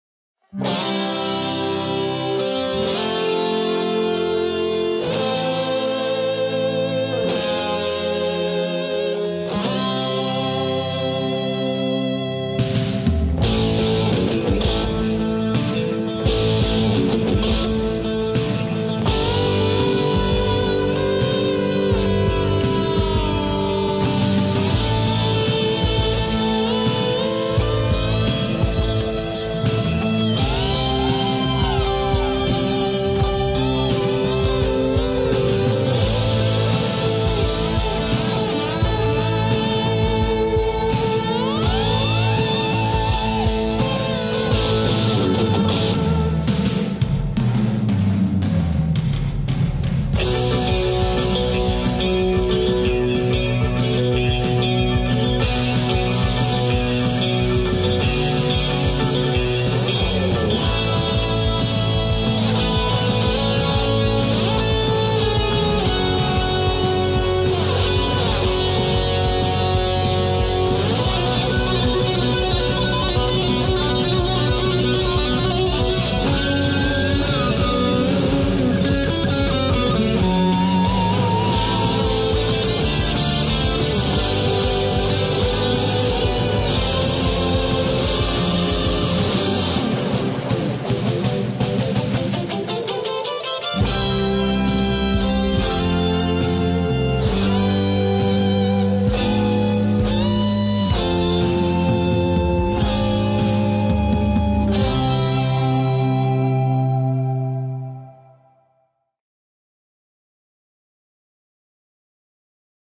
The MXR Rockman X100 Analog Tone Processor captures the sound of a thousand hits! 🎸 Based on Tom Scholz's legendary headphone amp/signal processor, this pedal recreates all the magic that made the original a secret weapon for big, textured, and expressive 1980s rock tones. t's a rig in a box that sits right on your pedalboard--offering the crystalline cleans, crunchy harmonics, and shimmering modulation that defined that sound.